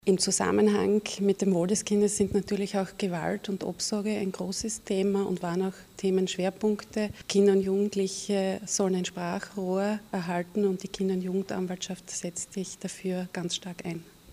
Kinder- und Jugendanwältin Brigitte Pörsch: